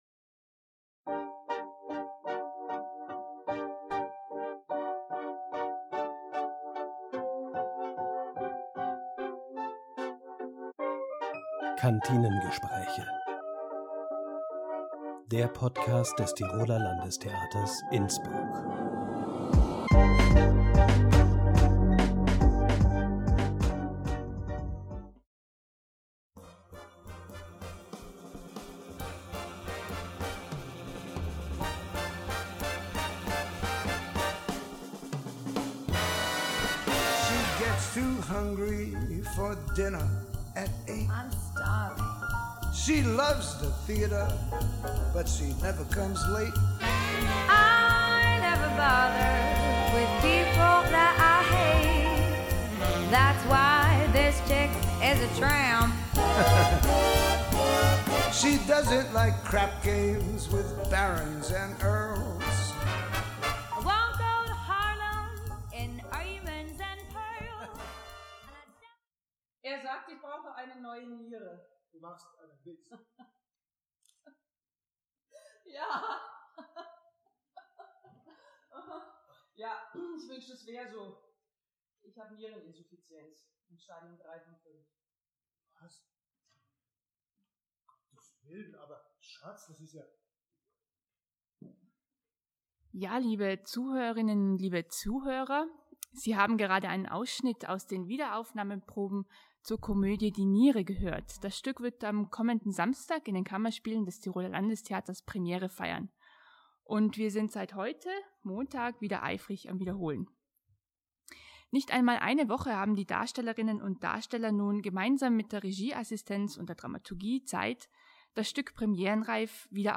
Kantinengespräche